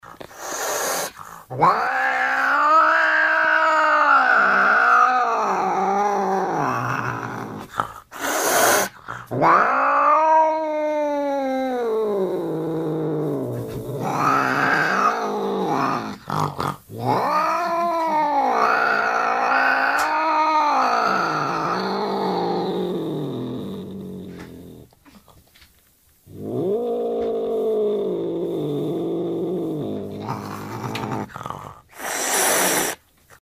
Звуки злого кота
На этой странице собраны звуки злого кота: агрессивное мяуканье, шипение, рычание и другие проявления кошачьего недовольства.
Самый злобный кот на свете